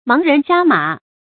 注音：ㄇㄤˊ ㄖㄣˊ ㄒㄧㄚ ㄇㄚˇ
盲人瞎馬的讀法